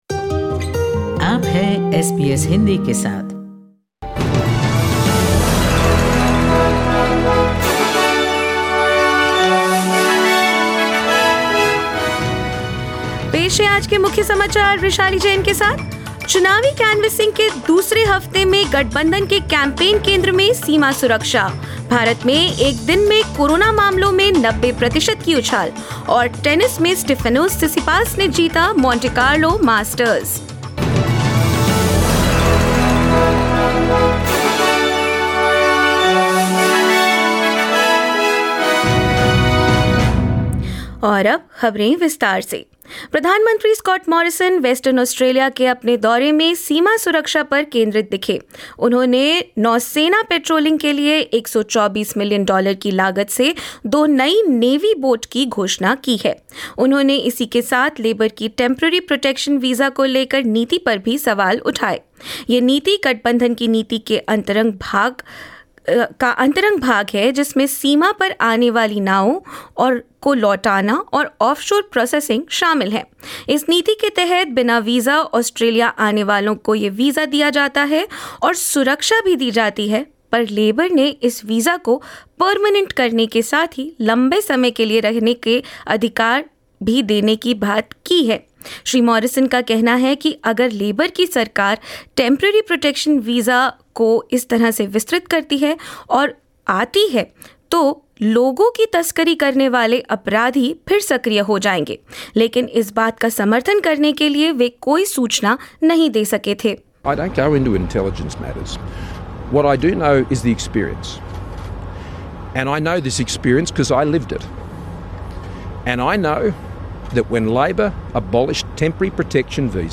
In this SBS Hindi bulletin: Prime Minister Scott Morrison announced $124 million for two new Navy patrol boats as a re-election promise while Labor maintained that Medicare has a bleak future under a Coalition government; In India, Coronavirus cases rise by 90 percent and more news.